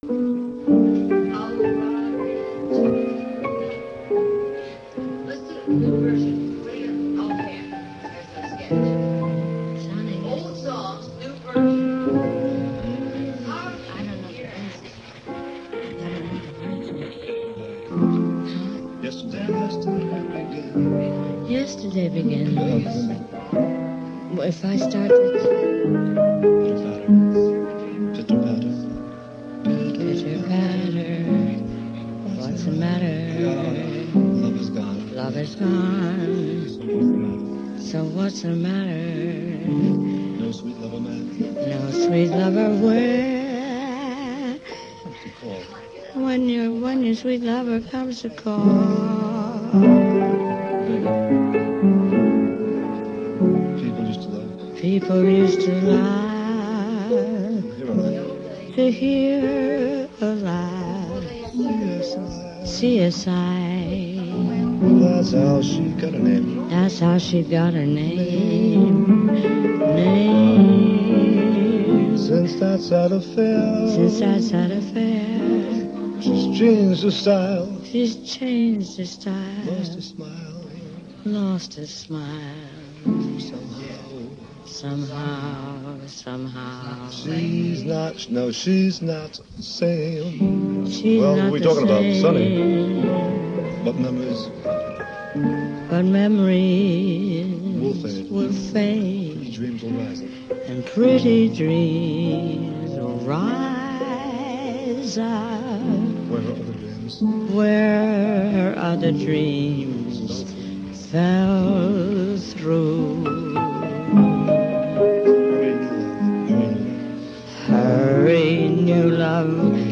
Impromptu “jam session” (what’s known to survive):